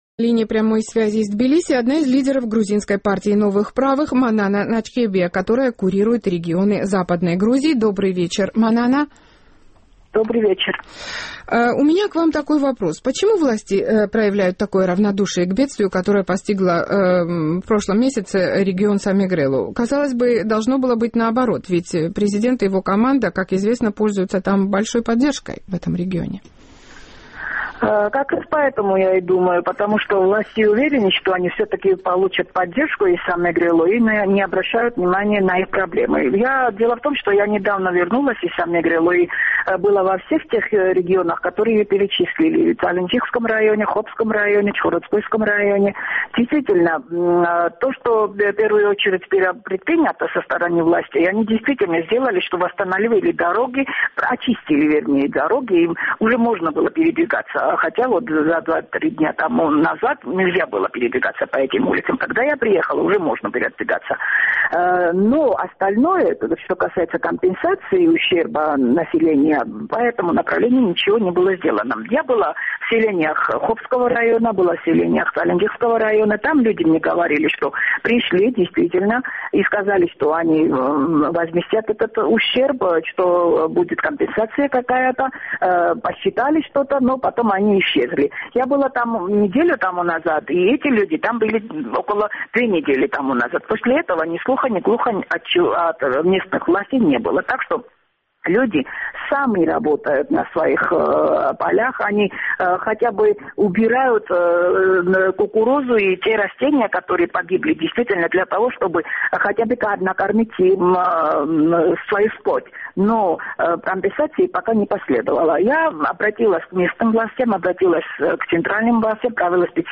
У нас линии прямой связи из Тбилиси